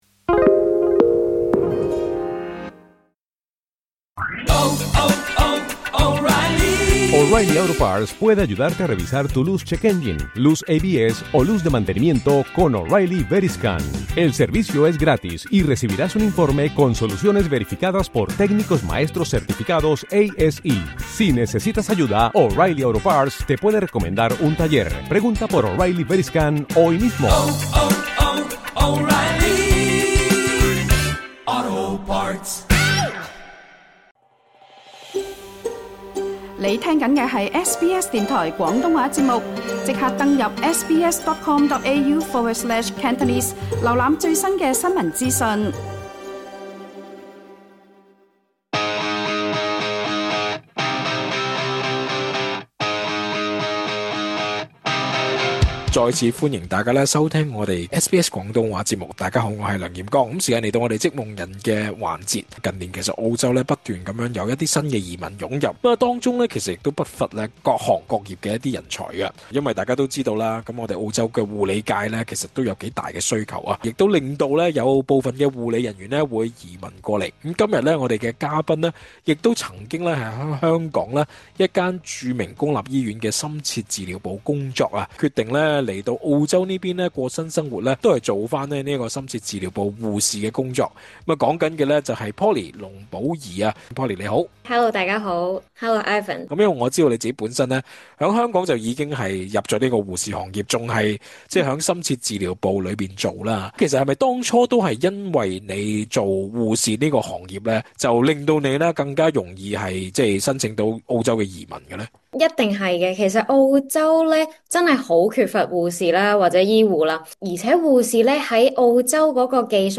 訪問詳盡錄音